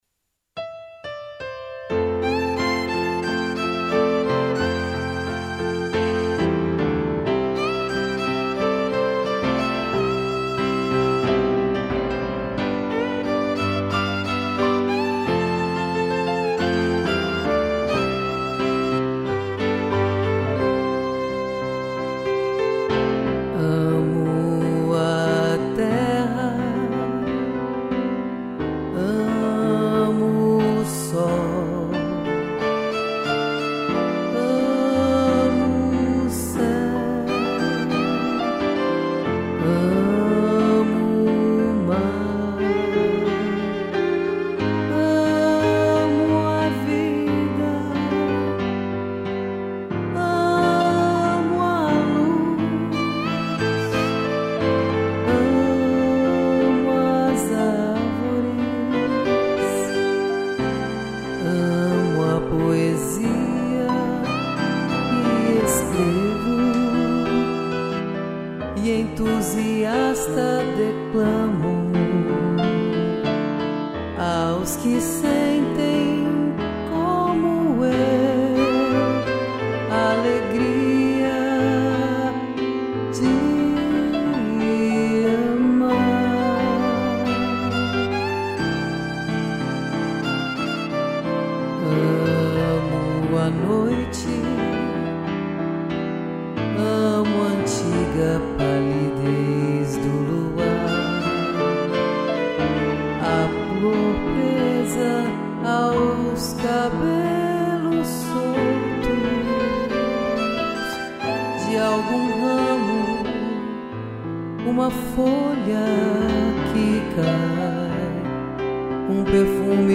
2 pianos e violino